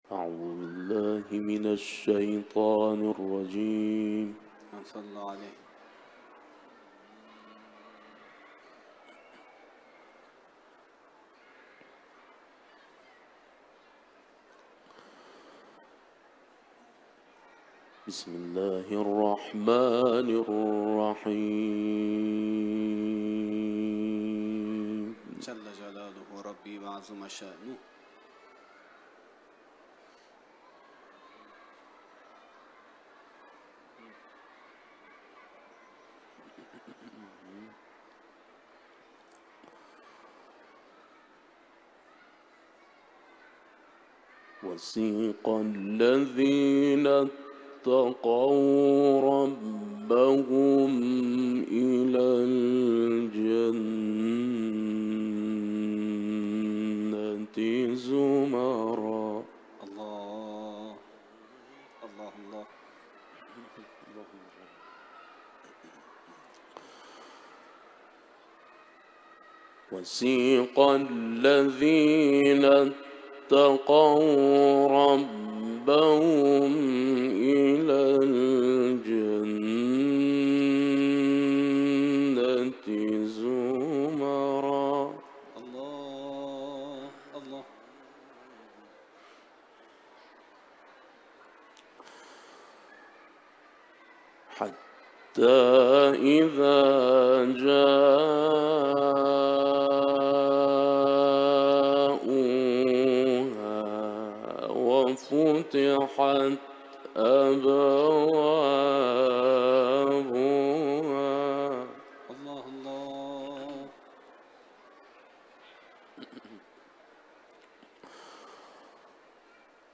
Kunci-kunci: Suara ، tilawah ، Haram Suci Imam Ridha